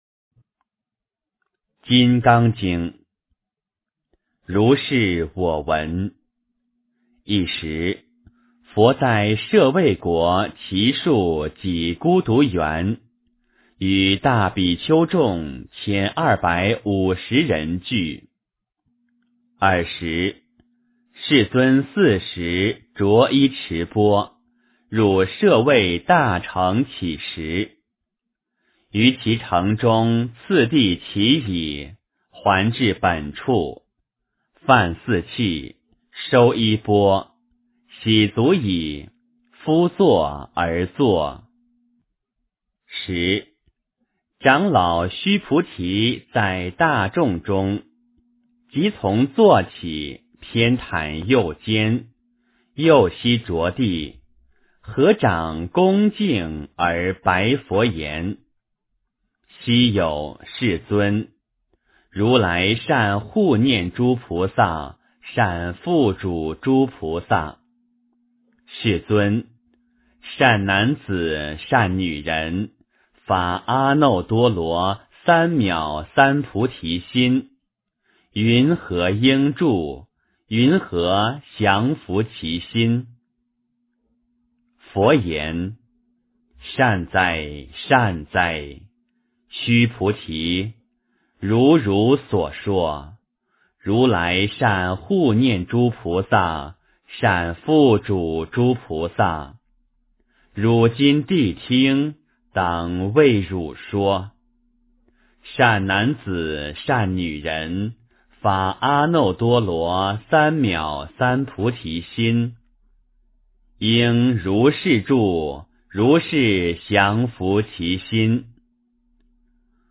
金刚经 - 诵经 - 云佛论坛